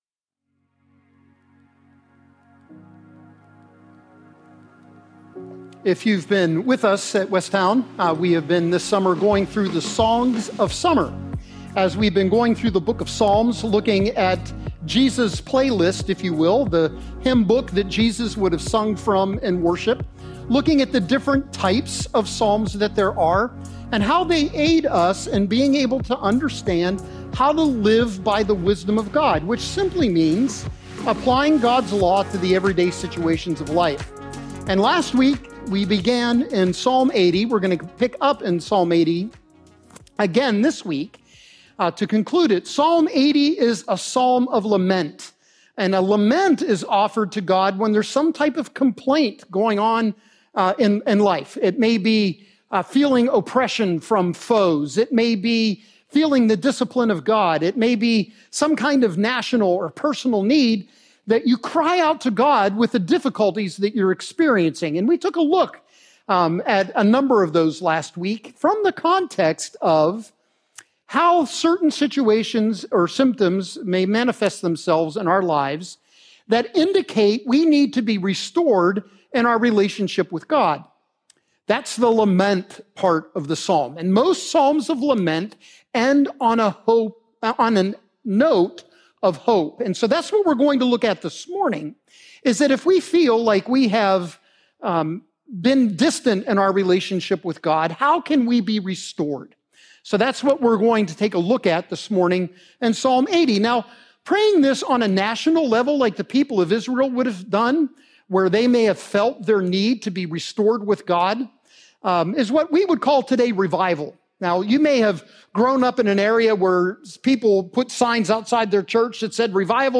This week, we will see from Psalm 80 – the biblical path to follow to restore our relationships with Christ. This week’s worship service will be a joyful celebration culminating in taking the Lord’s Supper together.